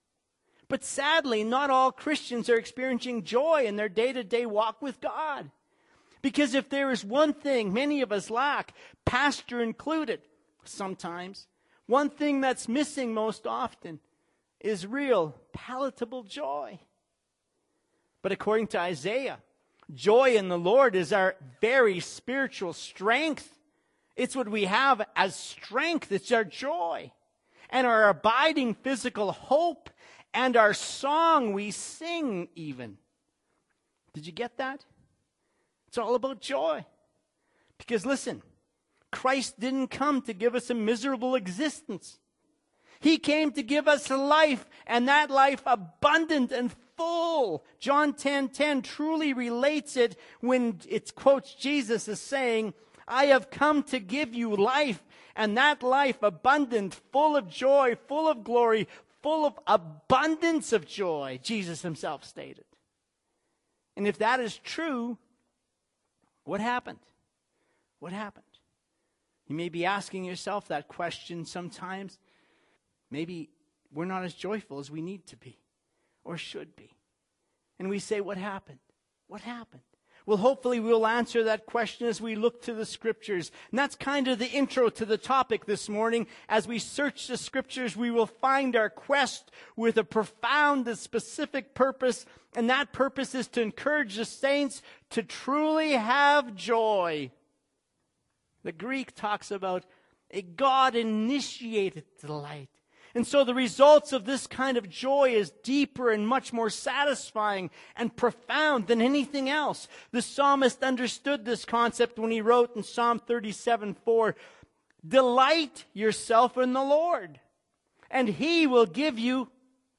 A SHORT SNIP OF A SERMON